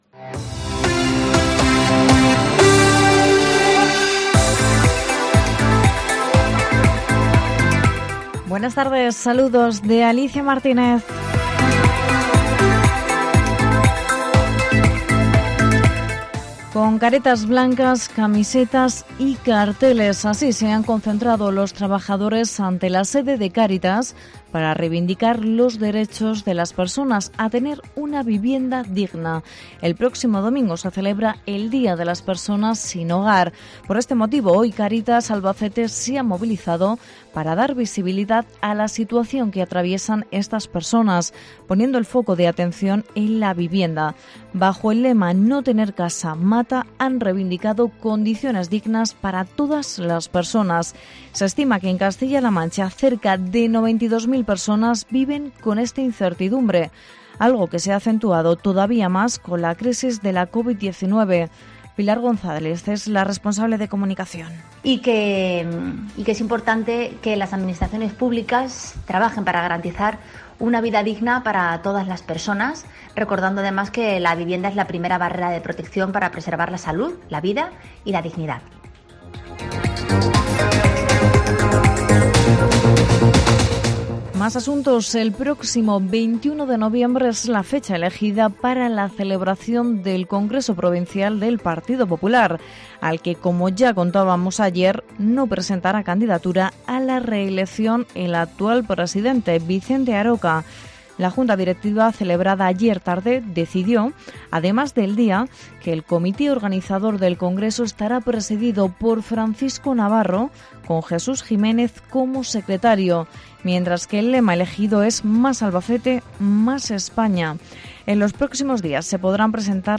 INFORMATIVO LOCAL 22 DE OCTUBRE